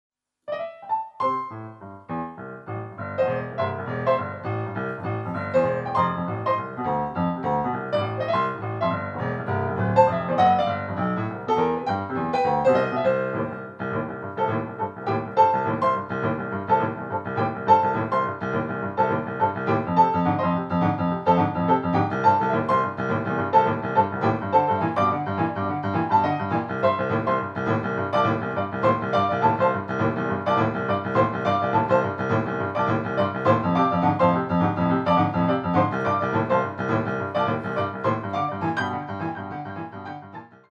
Style: Boogie Woogie Piano